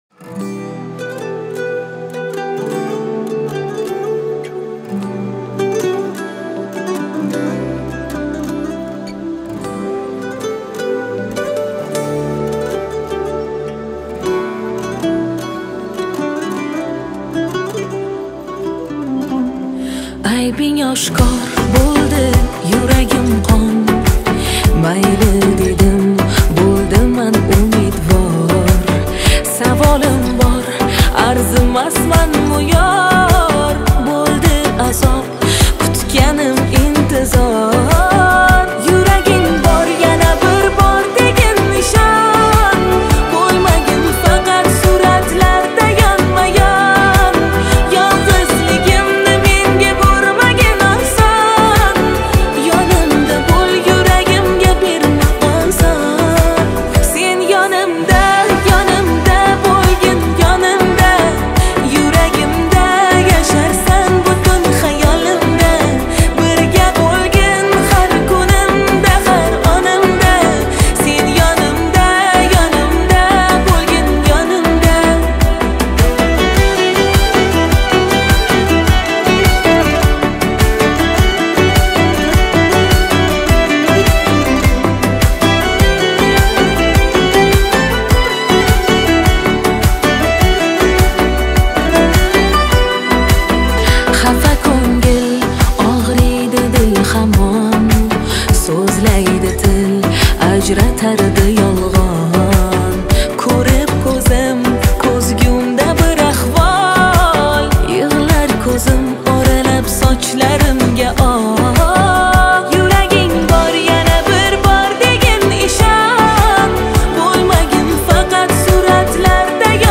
خواننده ازبکی